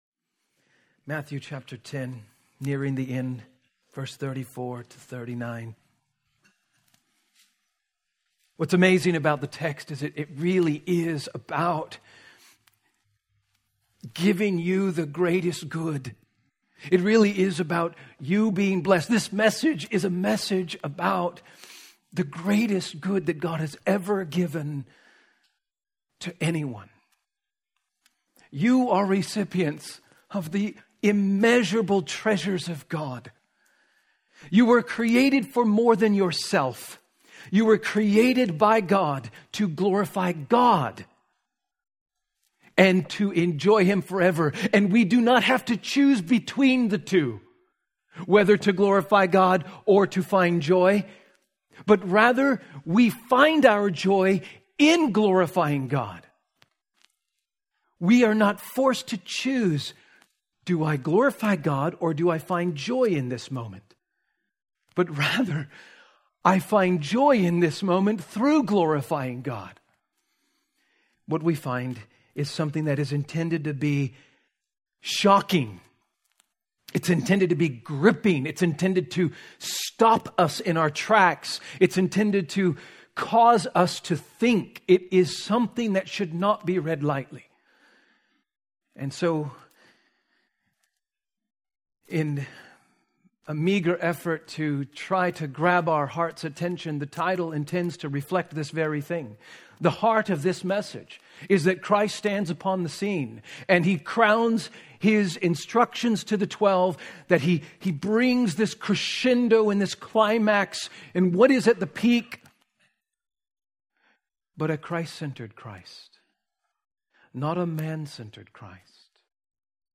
A message from the series "Manifesting the Messiah."